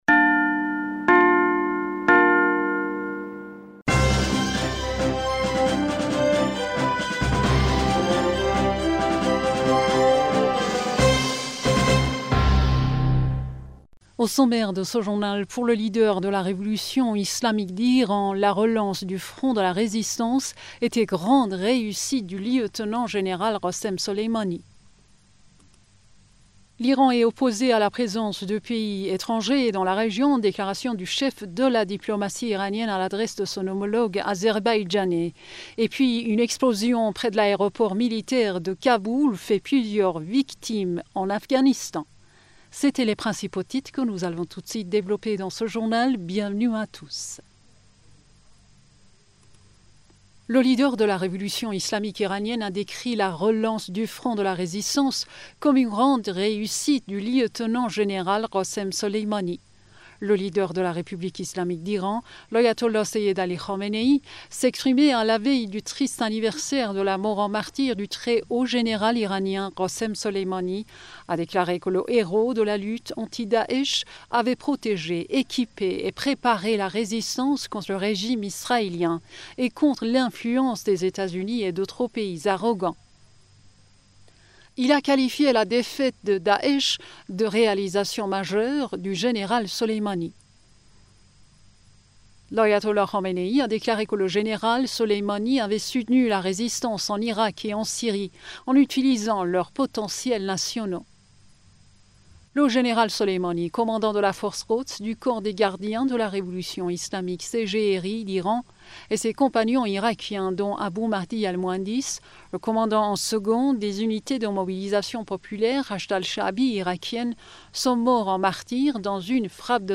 Bulletin d'information du 01 Janvier